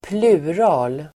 Ladda ner uttalet
Uttal: [²pl'u:ra:l]
plural.mp3